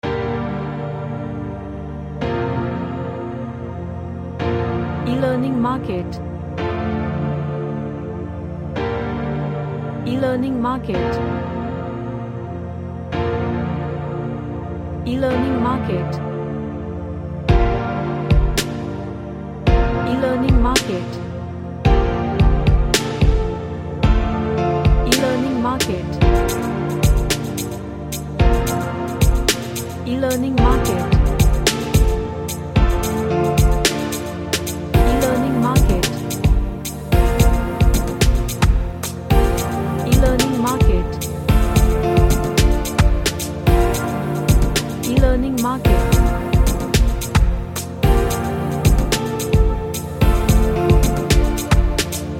A sad downtemo ambient track.
Sad / Nostalgic